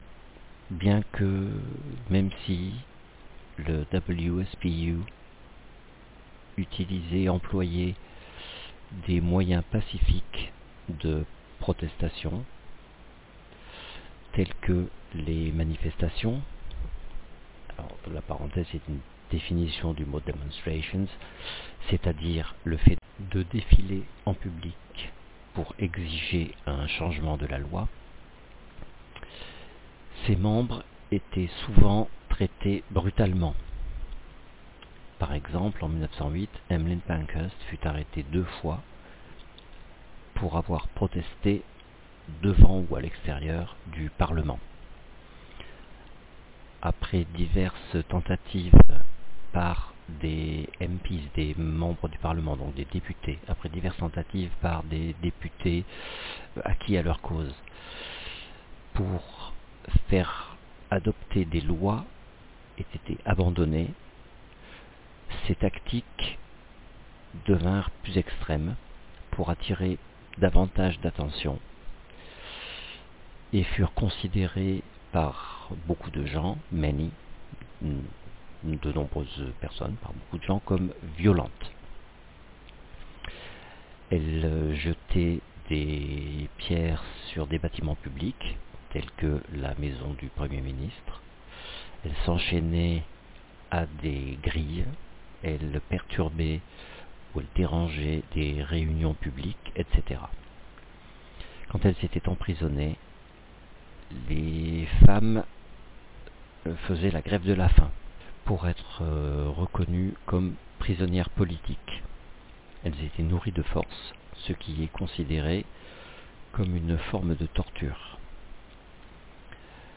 J'ai enregistré une traduction du texte "Suffragettes", à la demande d'une élève.